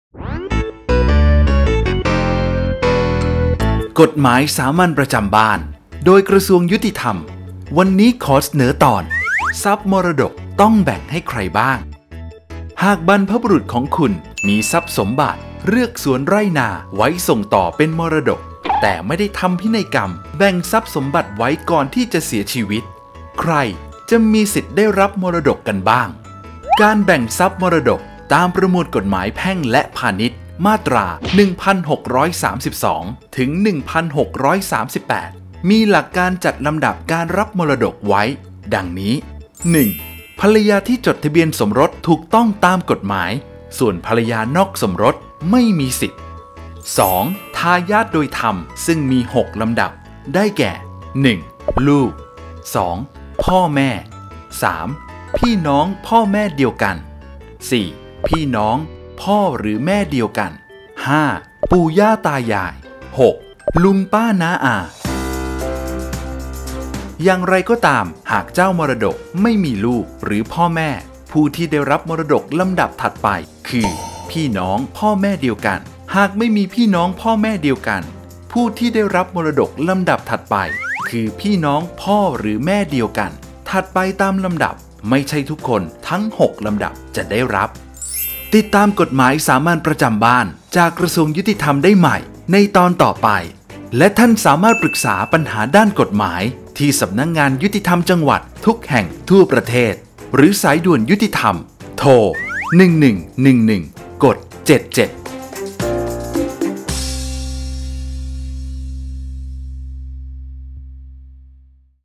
กฎหมายสามัญประจำบ้าน ฉบับภาษาท้องถิ่น ภาคกลาง ตอนทรัพย์มรดกต้องแบ่งให้ใครบ้าง
ลักษณะของสื่อ :   บรรยาย, คลิปเสียง